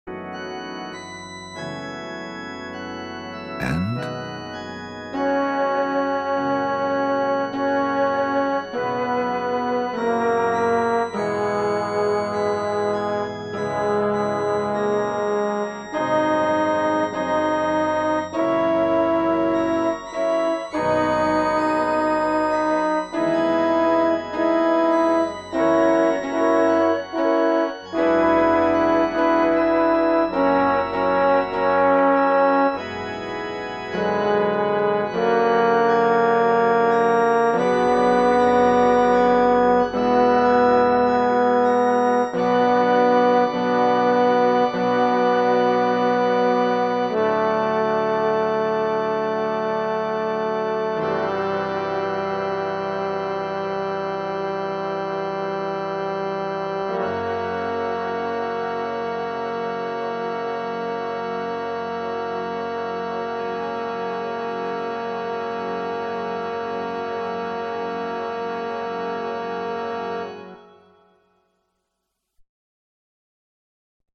There is no text, just your part.
1st Tenor